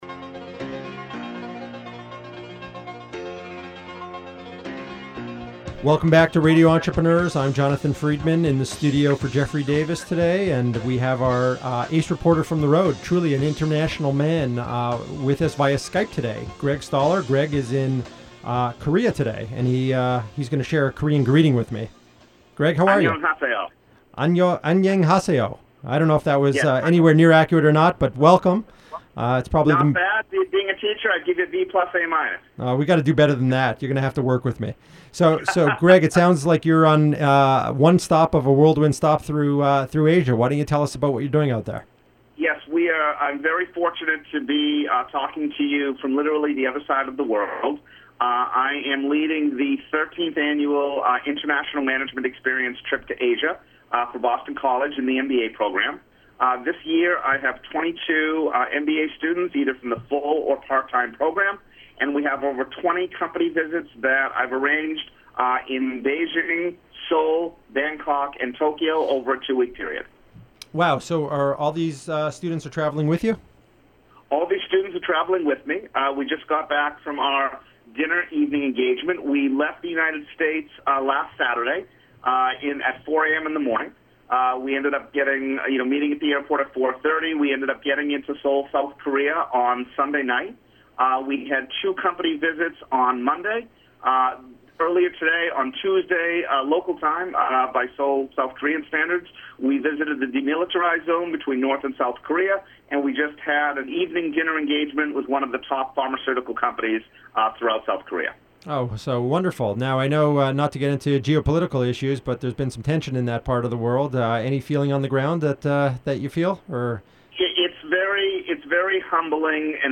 Here’s an interview on Radio Entrepreneurs, while in Seoul, South Korea, on the Boston College IME Asia trip.